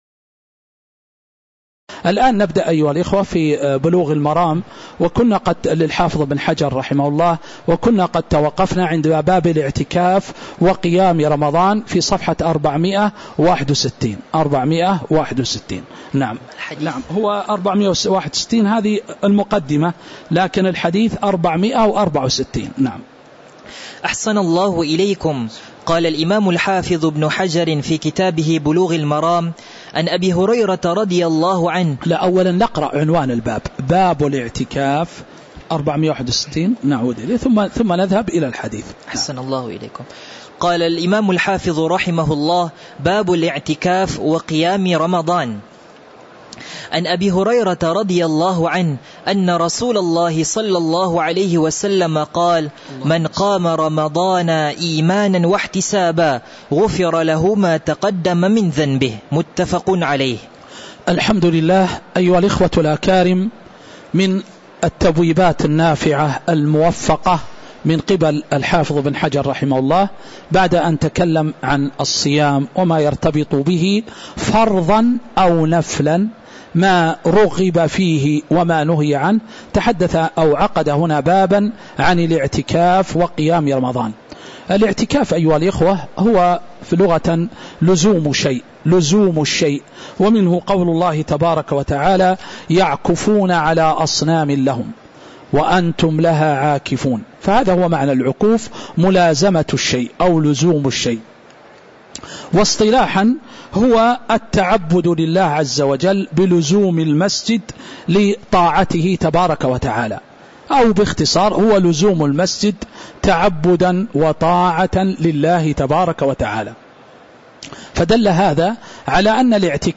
تاريخ النشر ٦ ربيع الأول ١٤٤٦ هـ المكان: المسجد النبوي الشيخ